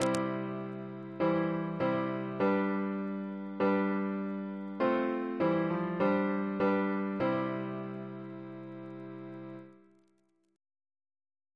CCP: Chant sampler